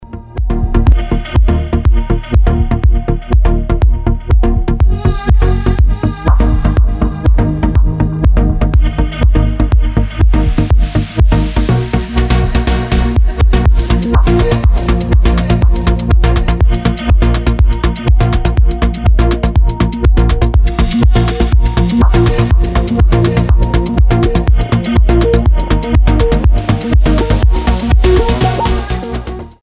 • Качество: 320, Stereo
атмосферные
без слов
басы
Стиль: progressive house